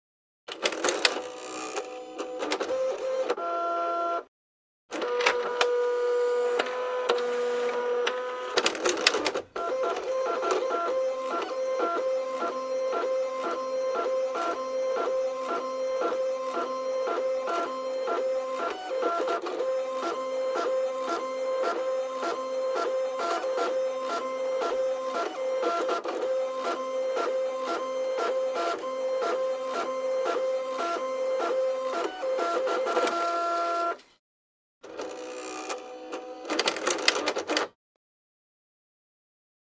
Ink Jet Printer | Sneak On The Lot
Ink Jet Printer; Desktop Ink Jet Printer; Turn On / Reset / Print One Page Of Text / Turn Off, Close Perspective.